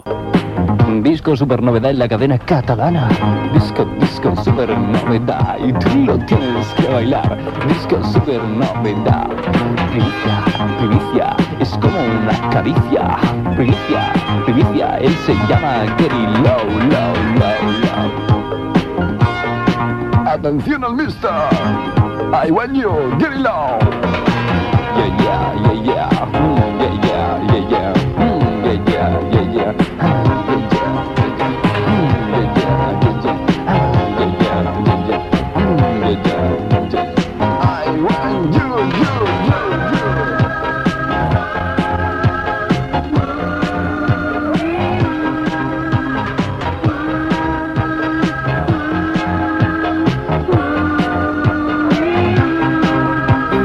Presentació d'un tema de música disco.
Musical